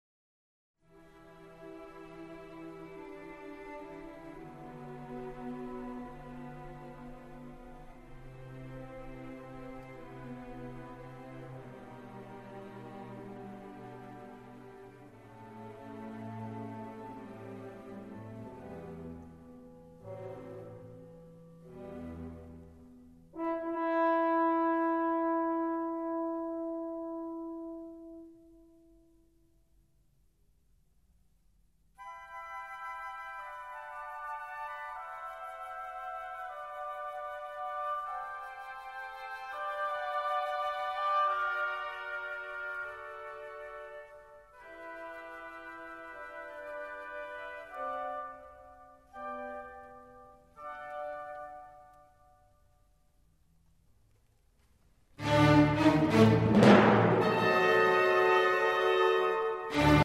Orchestral Works